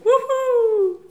ajout des sons enregistrés à l'afk ...
wouhou_01.wav